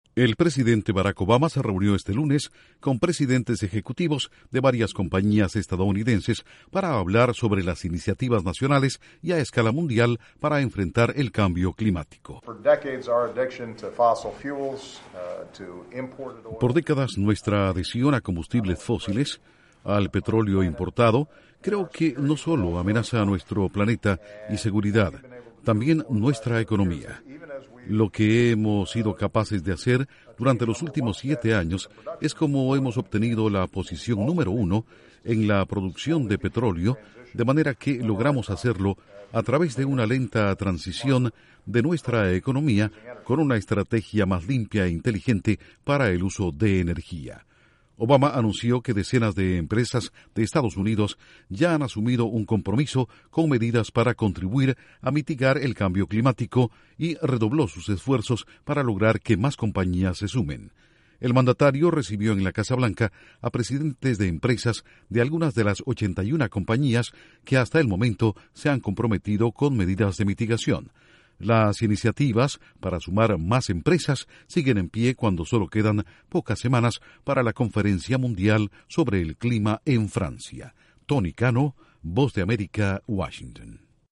Barack Obama se reúne en la Casa Blanca con empresarios que apoyan la iniciativa para mitigar el cambio climático, a pocas semanas de la cumbre mundial en París. Informa desde la Voz de América en Washington